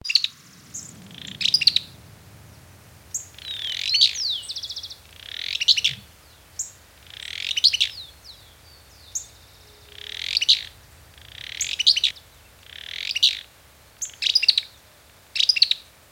Barullero (Euscarthmus meloryphus)
Nombre en inglés: Fulvous-crowned Scrub Tyrant
Fase de la vida: Adulto
Localidad o área protegida: Reserva Privada El Potrero de San Lorenzo, Gualeguaychú
Condición: Silvestre
Certeza: Fotografiada, Vocalización Grabada